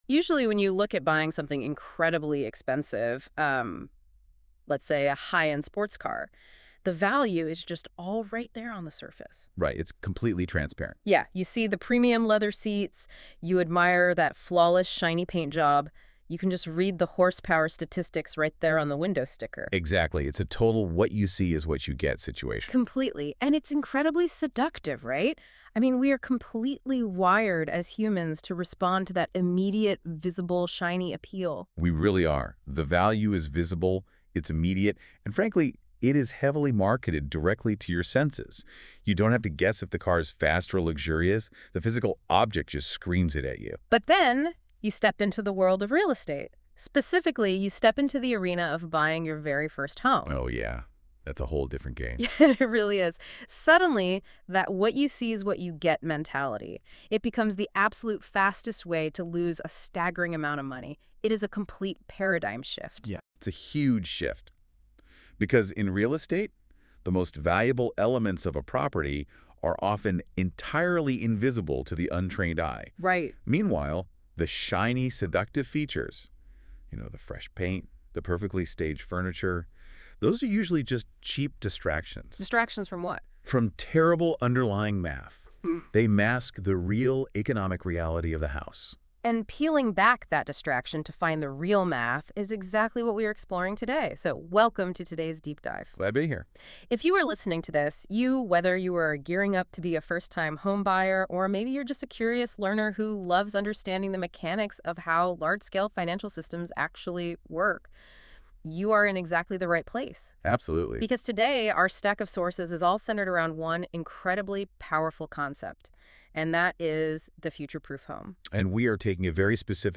In this conversational and encouraging episode, we move beyond just how to buy a house and focus on what to buy for long-term success and wealth building.